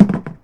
plastic_barrel_hard2.wav